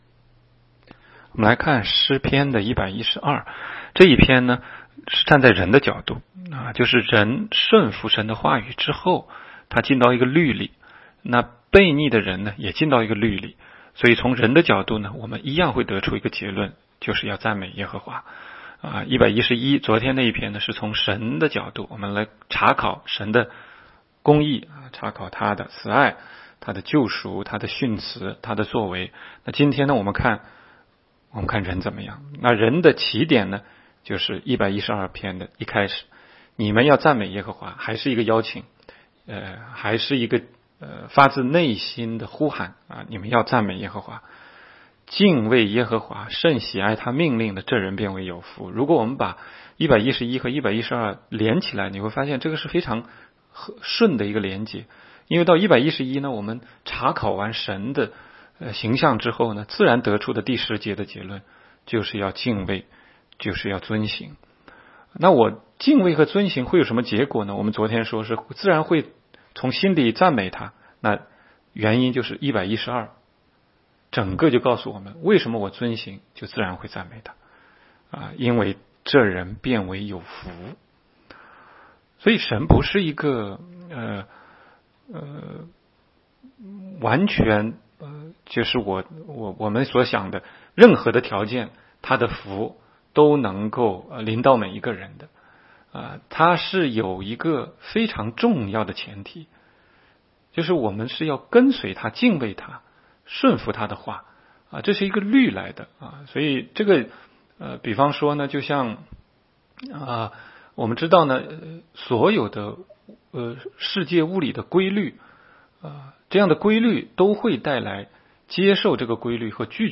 16街讲道录音 - 每日读经 -《 诗篇》112章